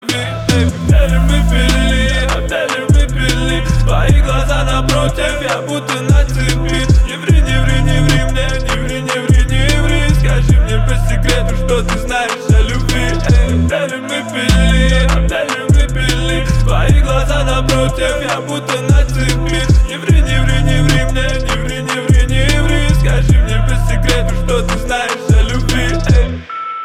• Качество: 320, Stereo
лирика
русский рэп
басы